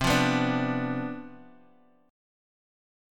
CM7sus2sus4 Chord